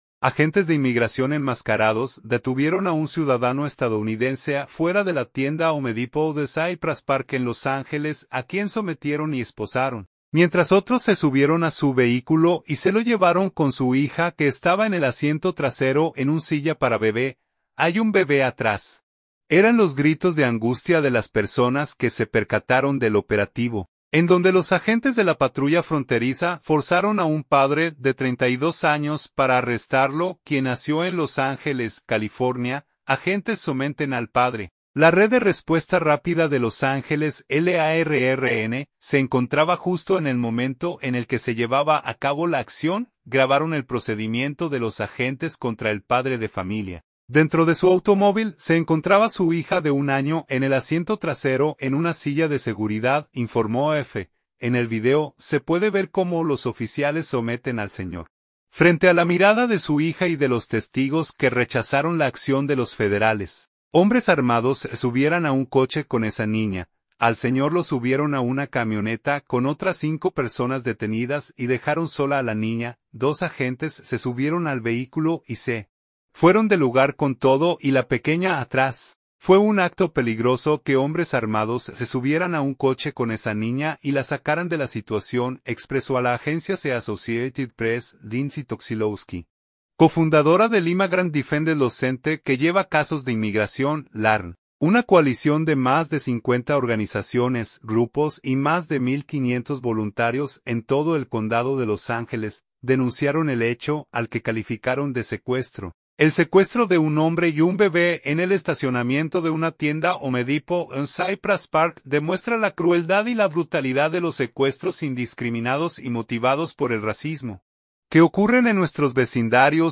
"¡Hay un bebé atrás!", gritaban las personas que fueron testigos de la detención del ciudadano estadounidense.